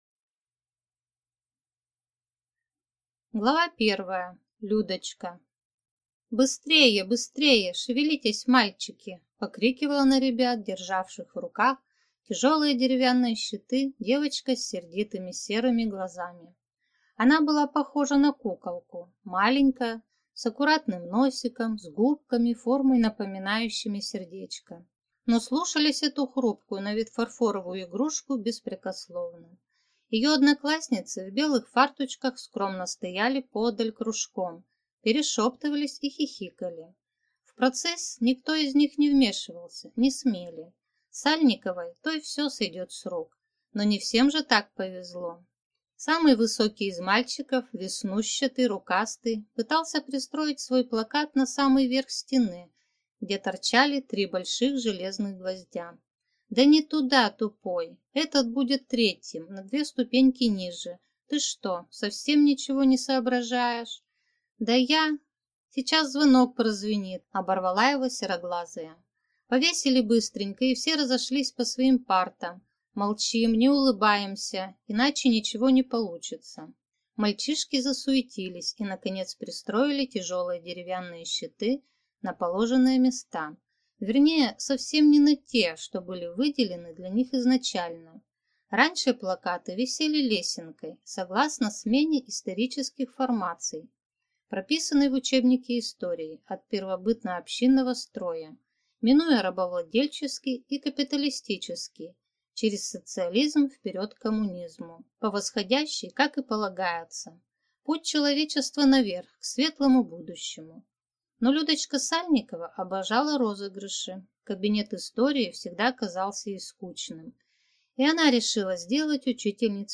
Аудиокнига Мертвым не мстят, или Шутка | Библиотека аудиокниг